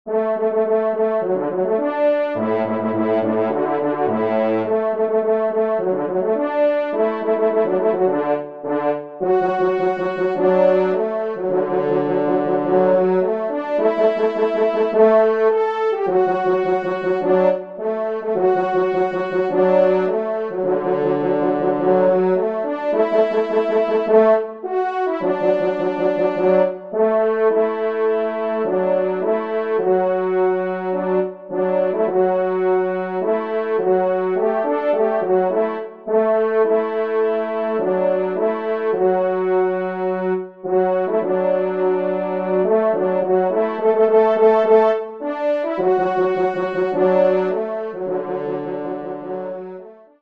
4e Trompe